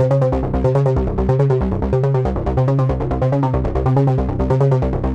Index of /musicradar/dystopian-drone-samples/Droney Arps/140bpm
DD_DroneyArp4_140-A.wav